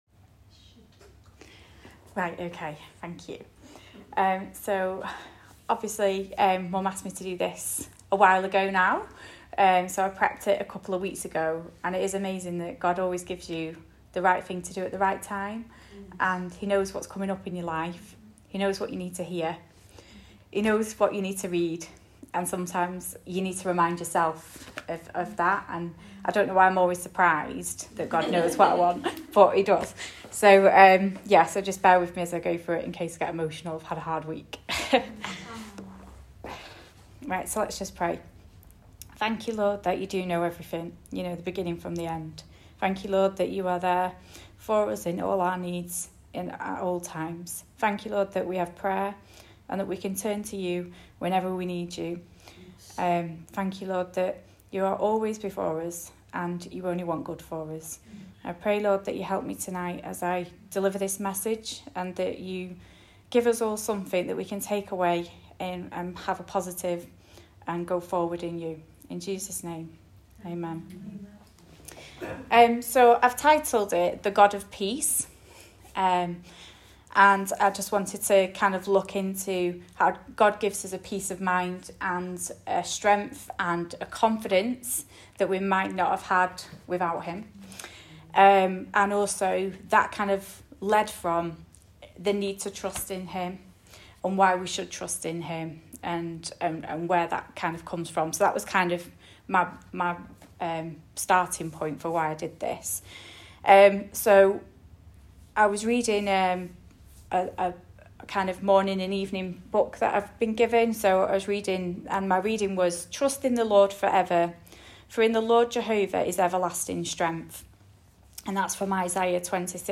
Ladies message